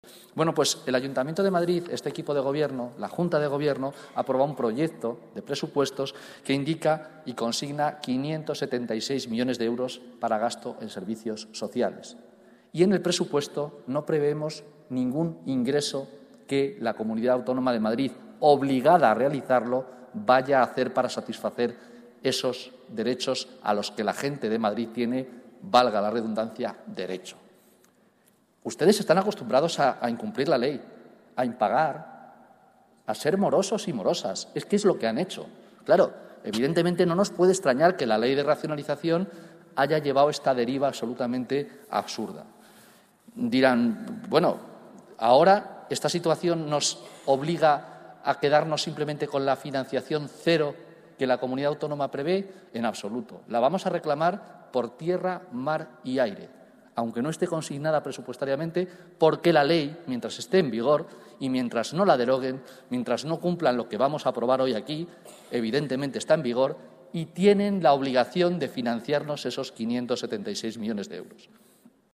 En el pleno ordinario que se ha celebrado hoy, miércoles 25